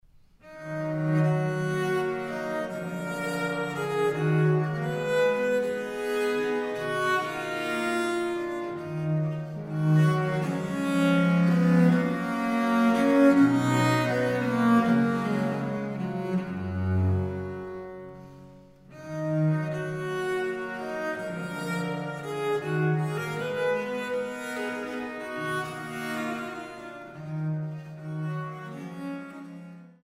2 Viole da Gamba